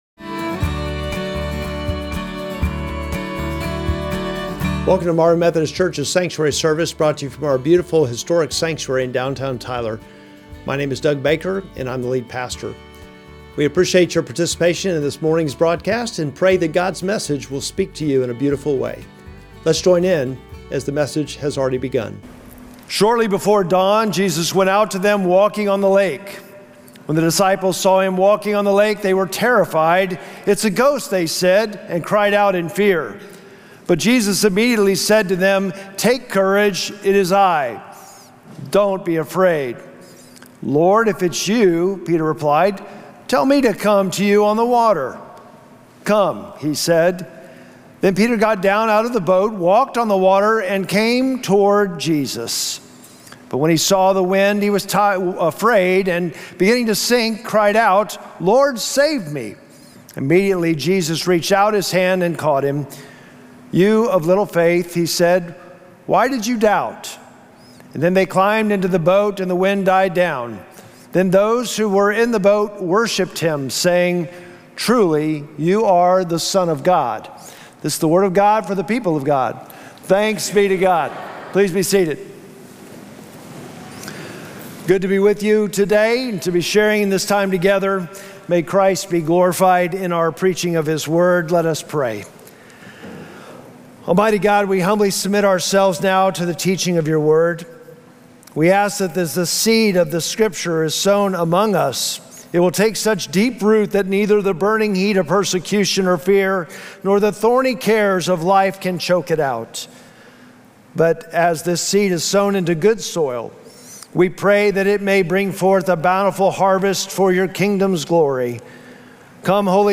Sermon text: Matthew 14:25-33